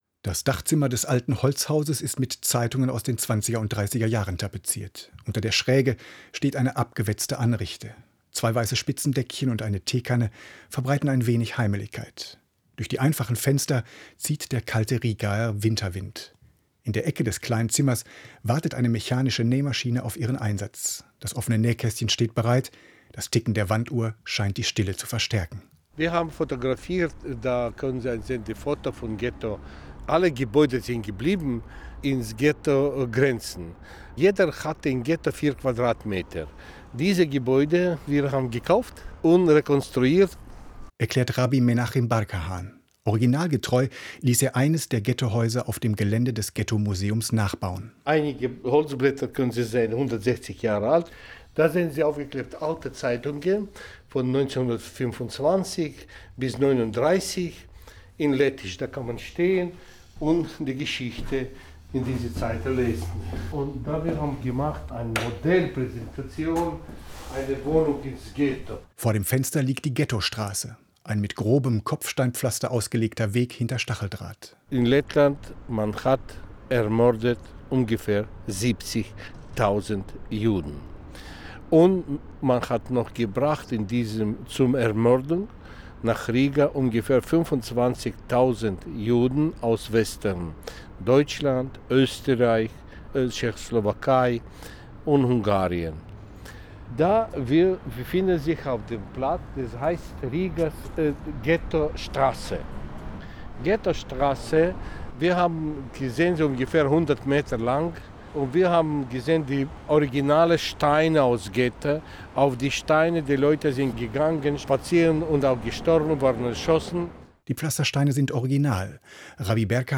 meine Reportage im Osteuropa-Magazin des WDR: